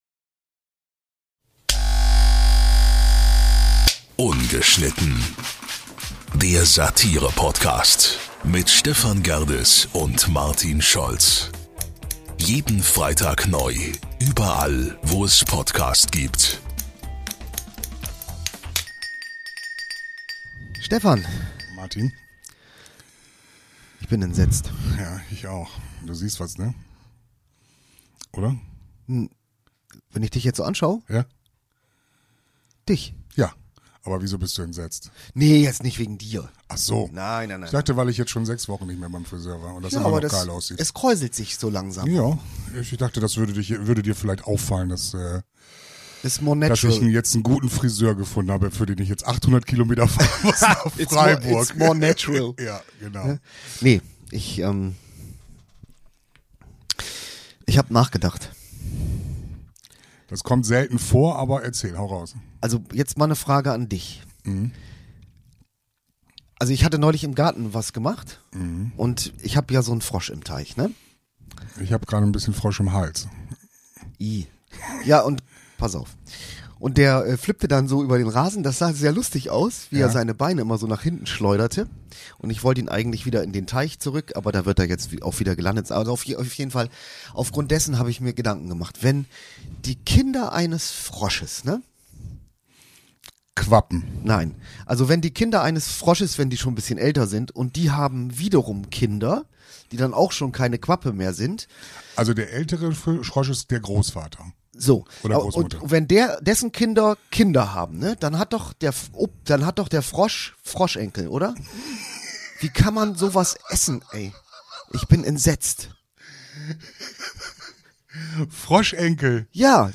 Wir bieten Euch viel gute Laune, Unterhaltung und Musik ohne Ende und dass ganz fresh und frei.